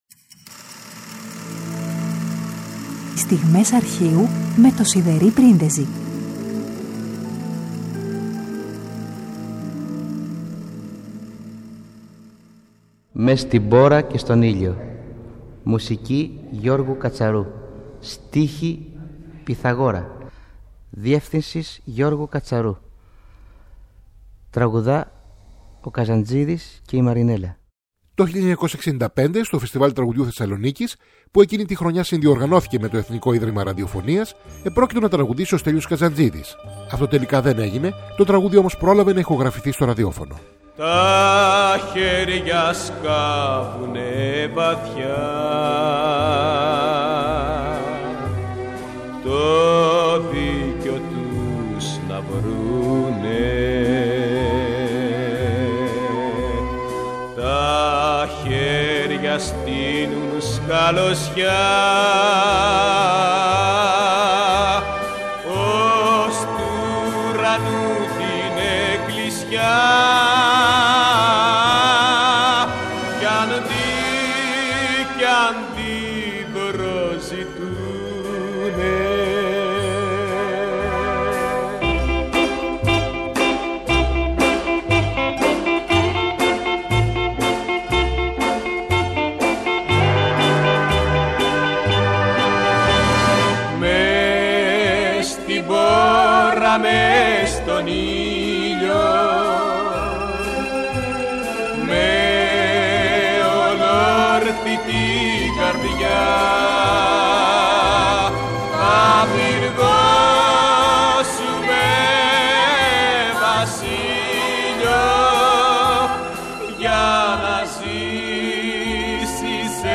Πέμπτη 31 Μαρτίου: Το 1965, στο Φεστιβάλ Τραγουδιού Θεσσαλονίκης, που εκείνη τη χρονιά συνδιοργανώθηκε από το Εθνικό Ίδρυμα Ραδιοφωνίας, επρόκειτο να λάβει μέρος ο Στέλιος Καζαντζίδης με το τραγούδι «Μες στη μπόρα, μες στον ήλιο» του Γιώργου Κατσαρού και του Πυθαγόρα. Αυτό τελικά δεν έγινε, το τραγούδι όμως είχε ηχογραφηθεί για το ραδιόφωνο.